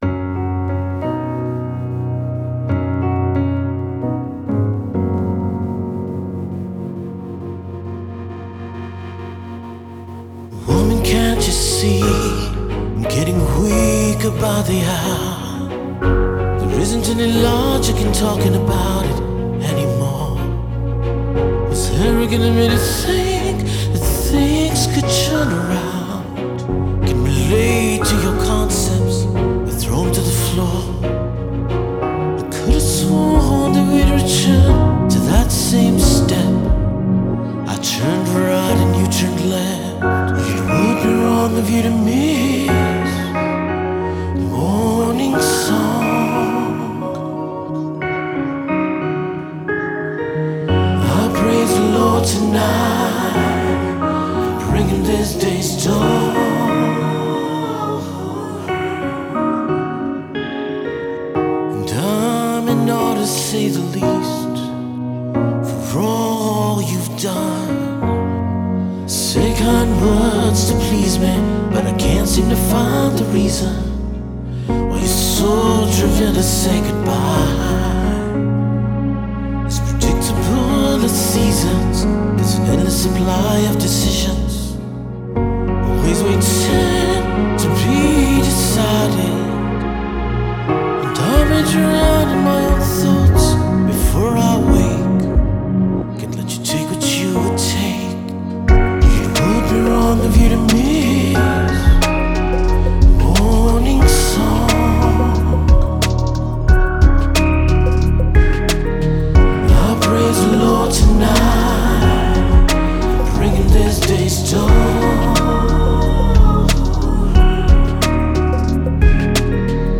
Alt Rock, Electronic